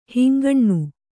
♪ hingaṇṇu